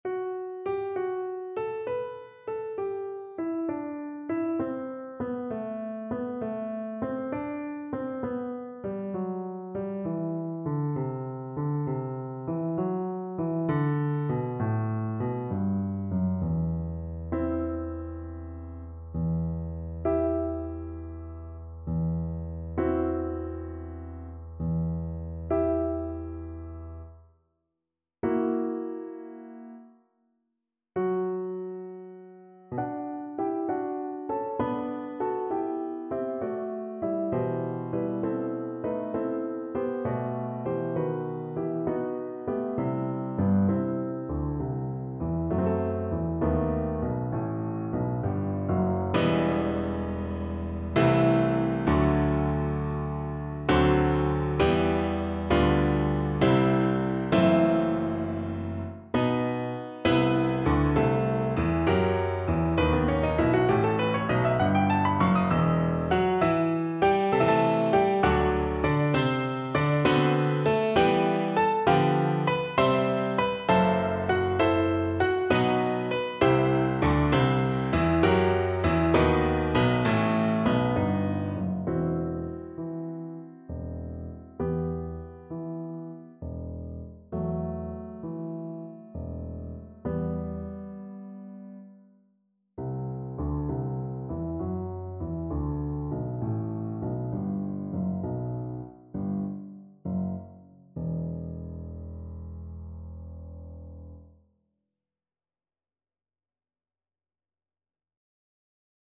Play (or use space bar on your keyboard) Pause Music Playalong - Piano Accompaniment Playalong Band Accompaniment not yet available transpose reset tempo print settings full screen
Viola
E minor (Sounding Pitch) (View more E minor Music for Viola )
9/8 (View more 9/8 Music)
Moderato . = 66
Classical (View more Classical Viola Music)